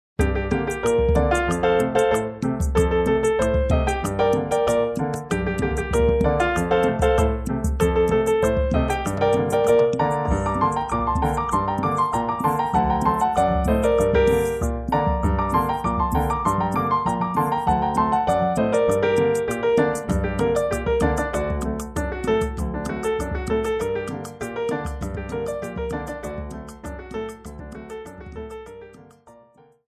– Partitura Piano Solo 🎹
• 🎼 Tonalidad: Gm